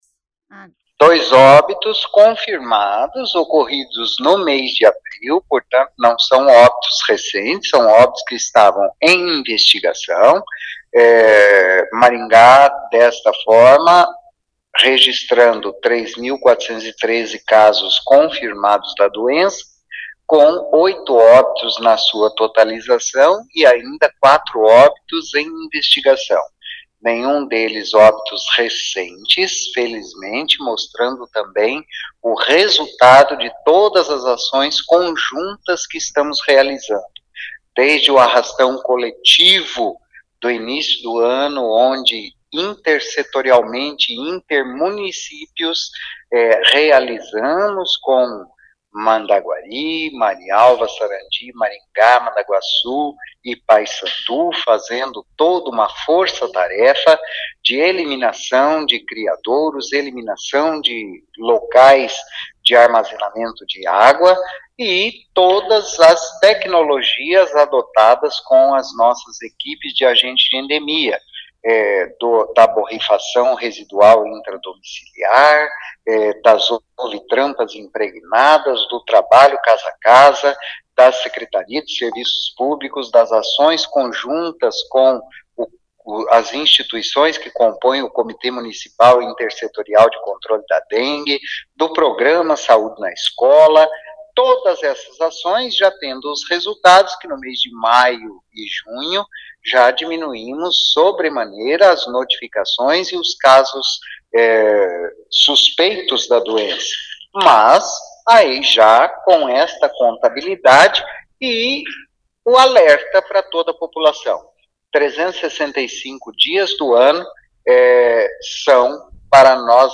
Ouça o que diz o secretário de Saúde Antônio Carlos Nardi: